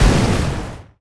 attack_explo_1.wav